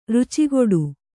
♪ rucigoḍu